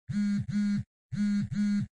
phone-vibration.wav